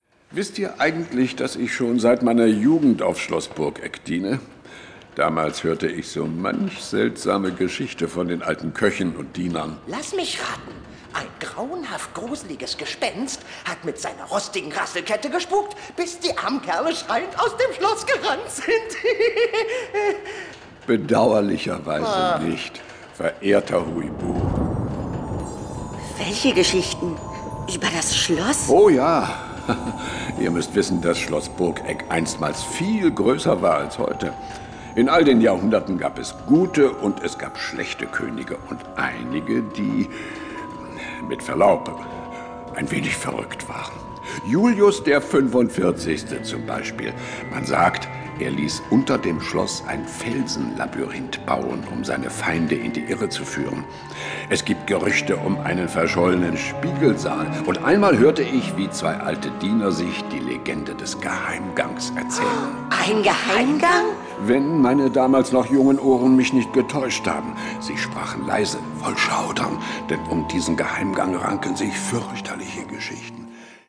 Ravensburger HUI BUH Neue Welt - Der verfluchte Geheimgang ✔ tiptoi® Hörbuch ab 4 Jahren ✔ Jetzt online herunterladen!